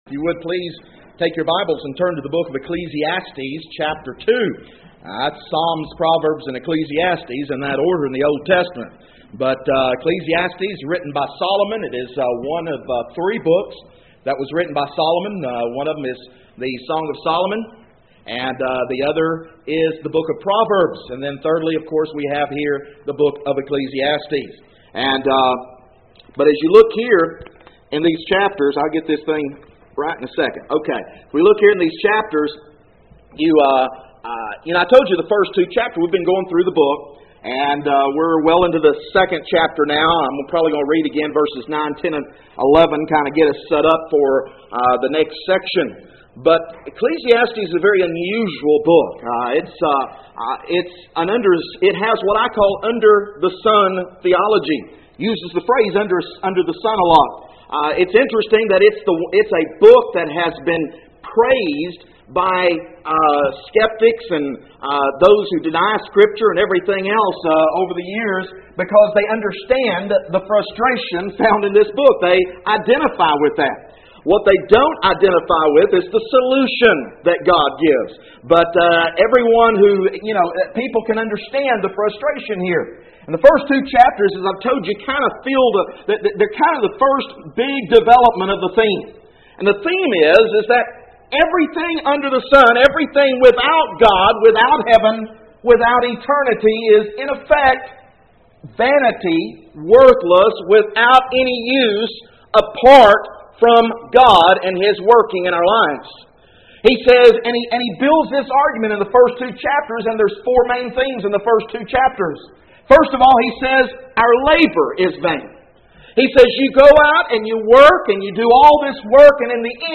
Text: Ecclesiastes 2:9-11 No Outline at this point. For More Information: More Audio Sermons More Sermon Outlines Join the Learn the Bible mailing list Email: Send Page To a Friend